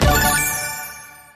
Win_Frame_Sound.MP3